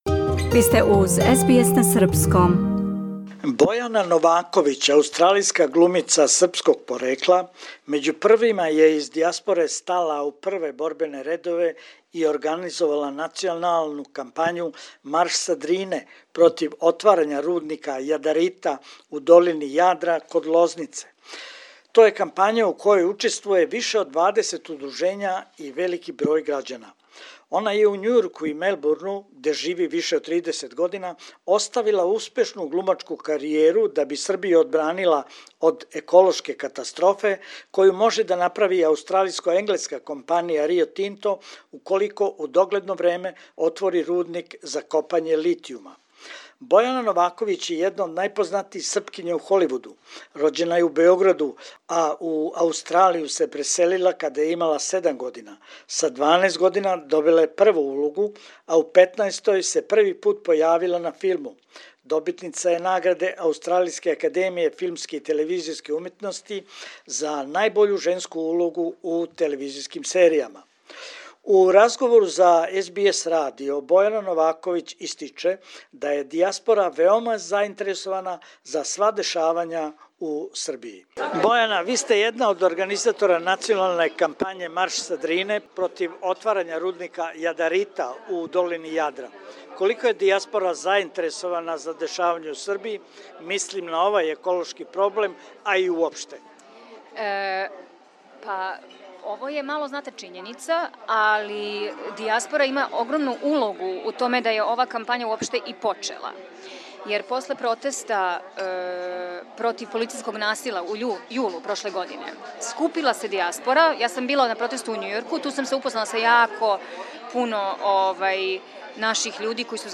У разговору за СБС радио Бојана Новаковић истиче да је дијаспора веома заинтересована за сва дешавања у Србији.